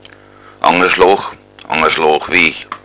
Originaltext: Dial. anga_schlooch, Abteilung im Staatswald, zum Waltinger Grund abhängend.